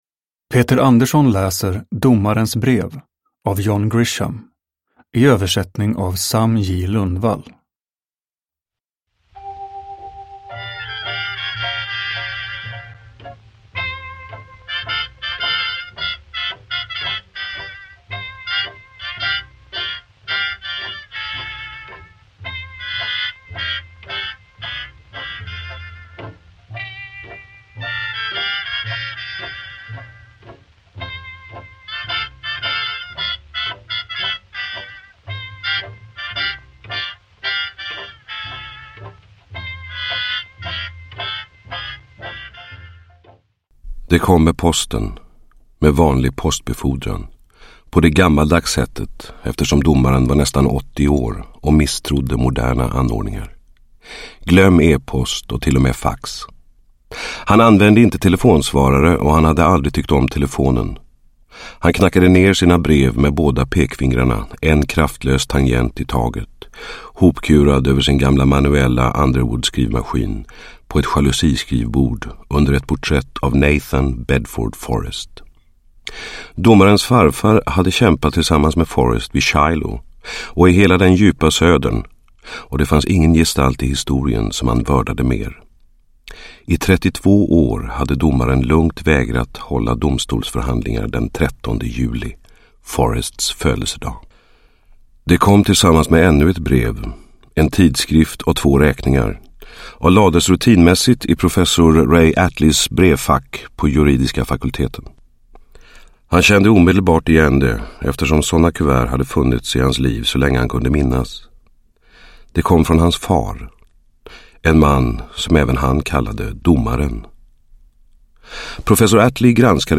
Domarens brev – Ljudbok – Laddas ner